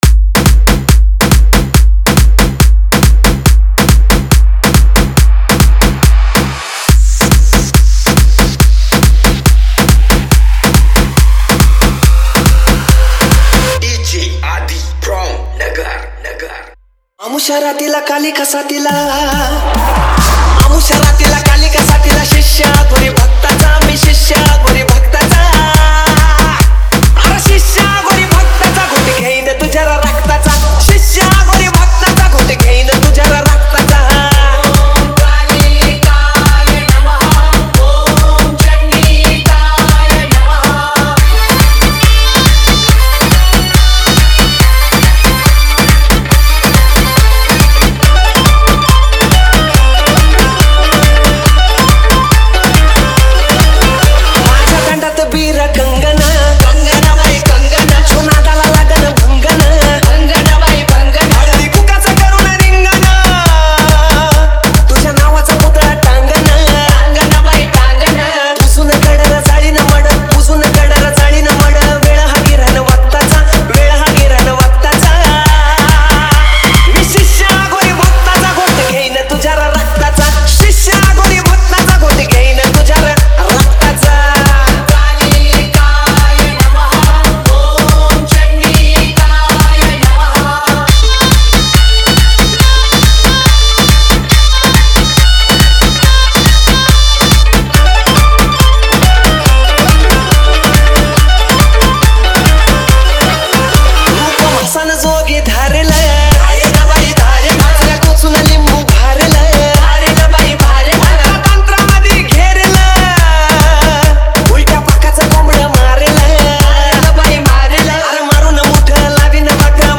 #1 New Marathi Dj Song Album Latest Remix Releases